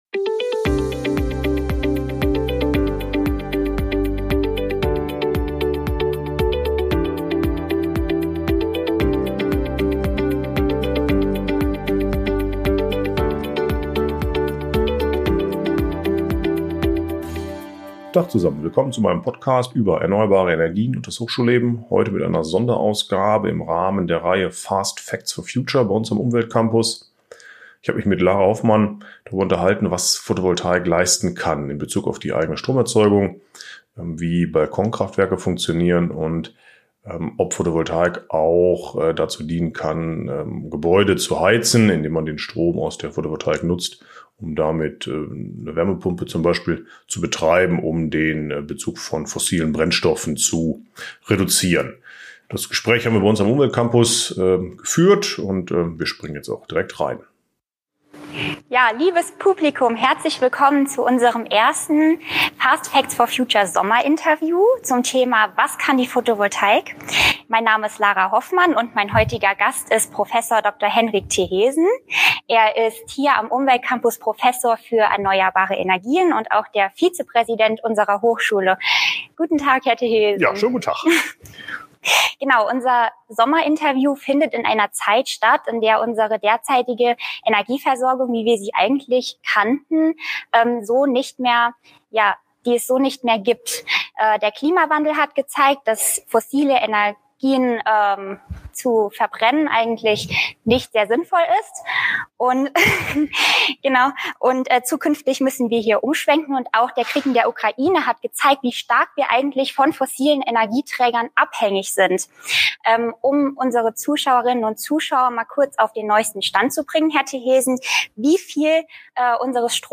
Im Rahmen des Sommerinterviews der Reihe "Fast Facts for Future" am Umwelt-Campus Birkenfeld